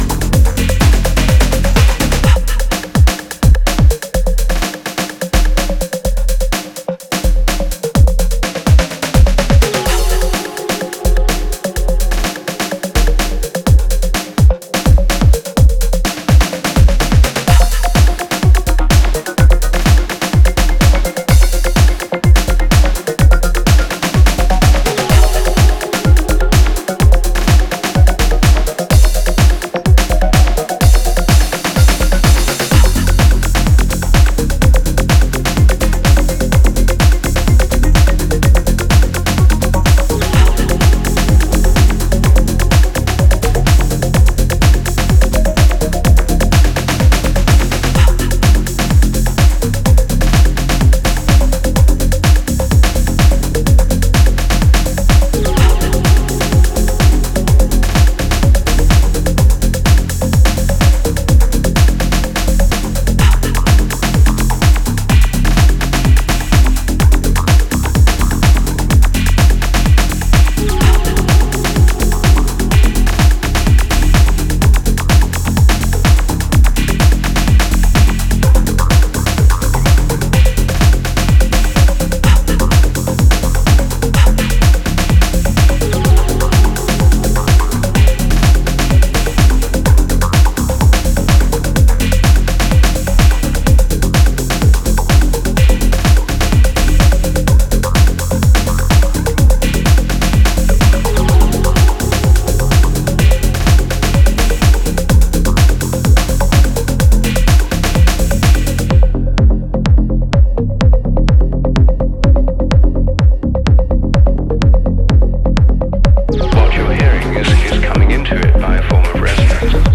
A sonic dystopia born in Montevideo.